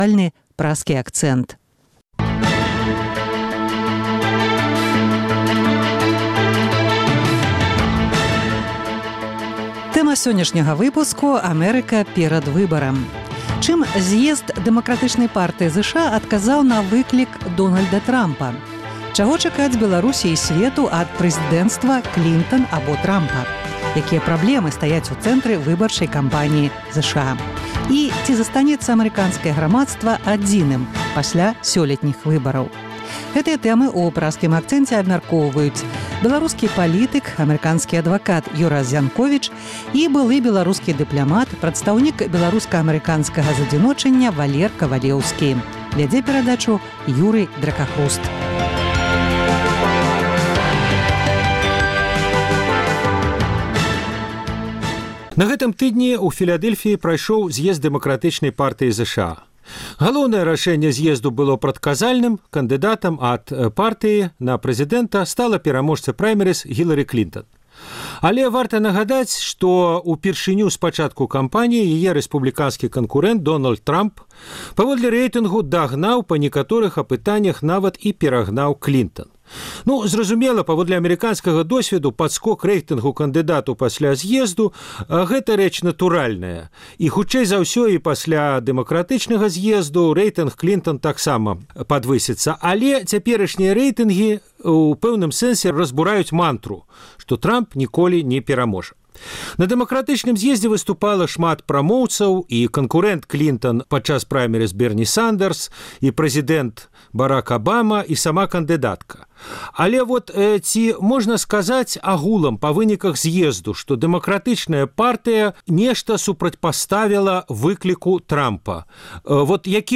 Гэтыя тэмы ў Праскім акцэнце абмяркоўваюць беларускі палітык, амэрыканскі адвакат